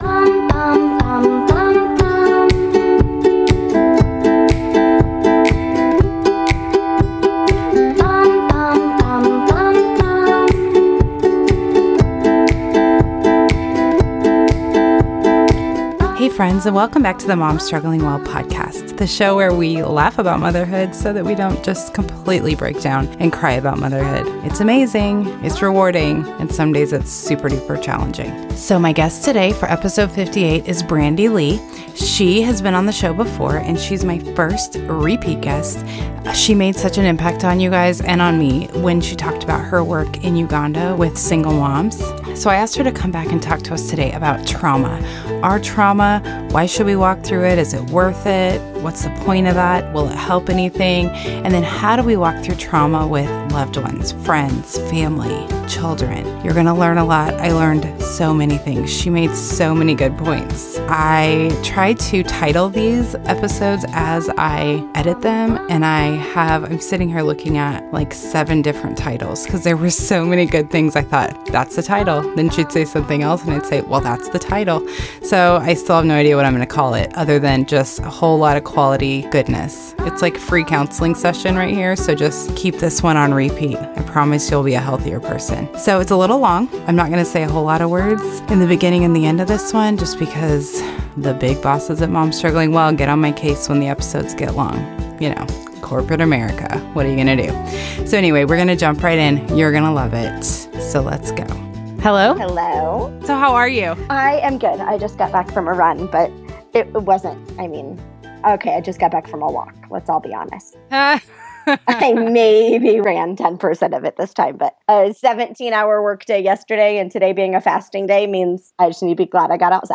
Don't worry, I edited most of them out. what we chat about: why it matters that we get healthy what our freedom exposes in other people the importance of validation in healing her one piece of advice for walking through trauma with someone